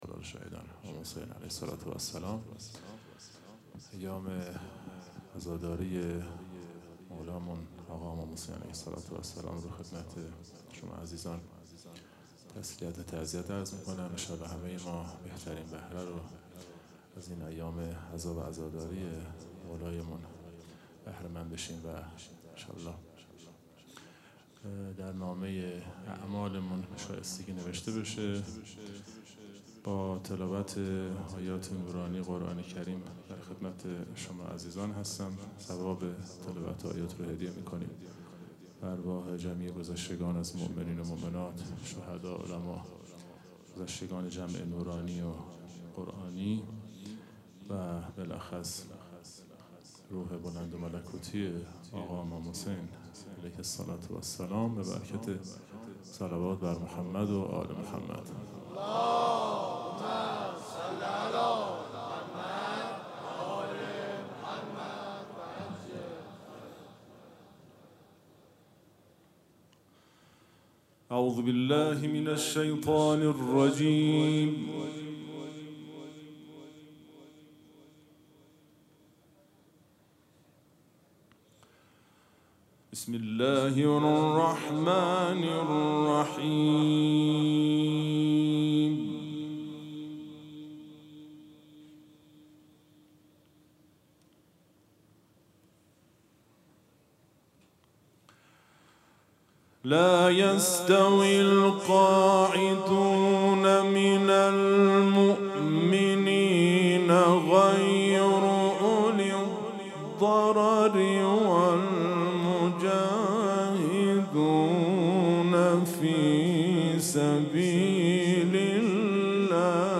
قرائت قرآن کریم
مراسم عزاداری شب اول محرم الحرام ۱۴۴۵
سبک اثــر قرائت قرآن